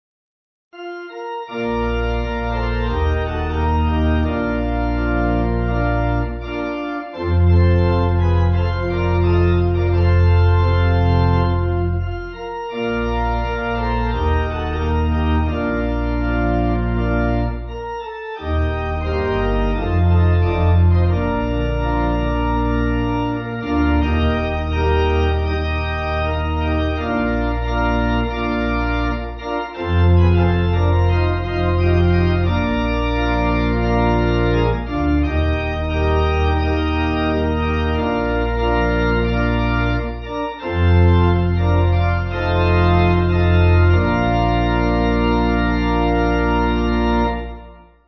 Hawaiin Folk Song
Organ
(CM)   3/Bb